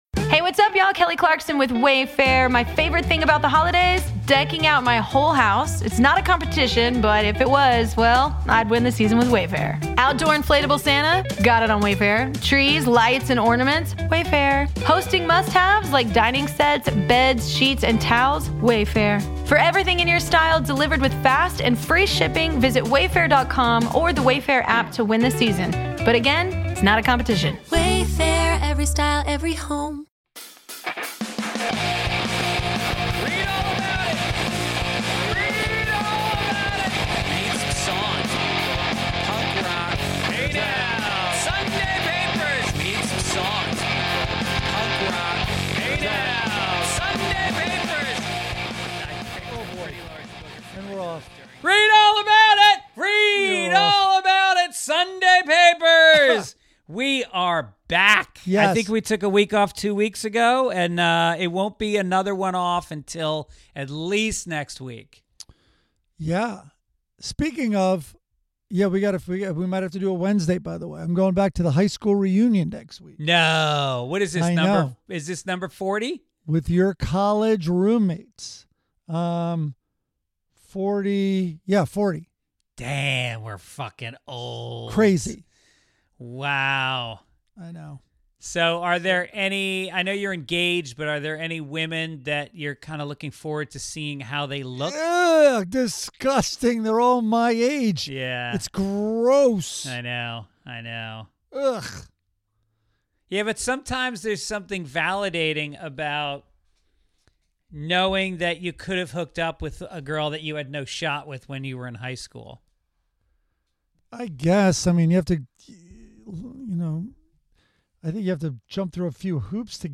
This week, Adam sits down with Karen Hao, author of EMPIRE OF AI: Dreams and Nightmares in Sam Altman’s OpenAI to talk about what it means for all of us when tech bros w…